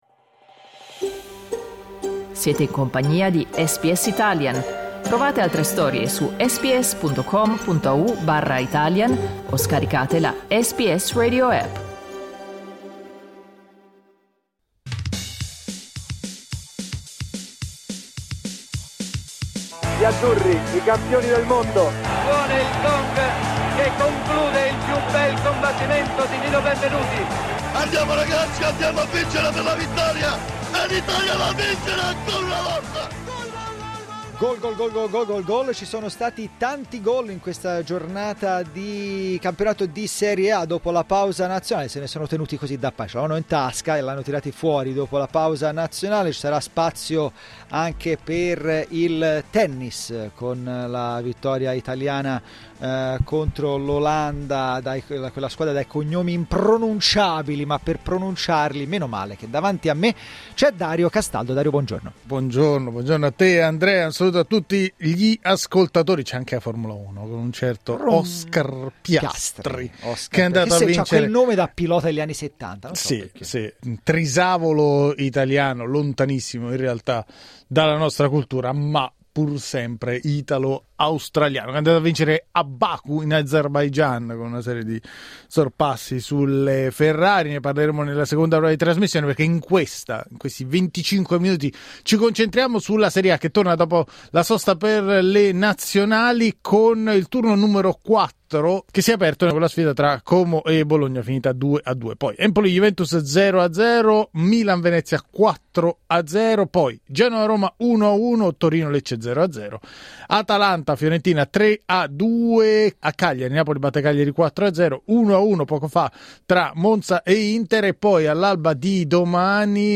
Antonio Conte, tecnico del Napoli, e Oscar Piastri, il pilota australiano della McLaren